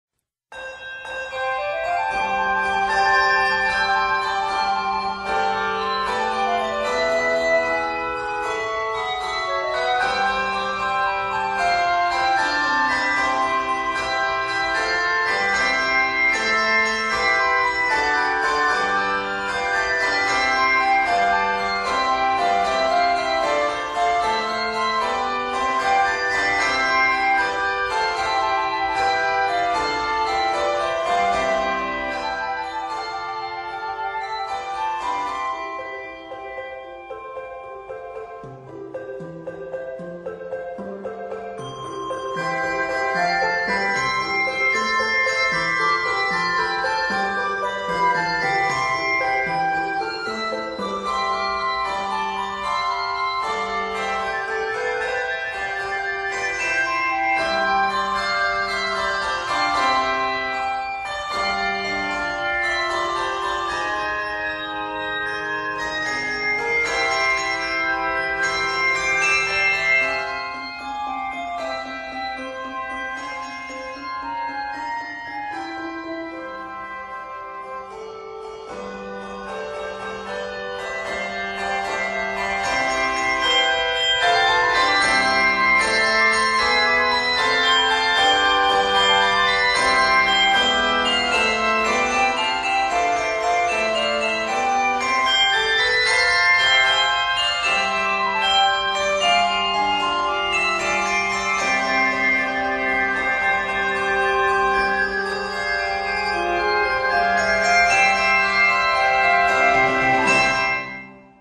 is arranged in C Major.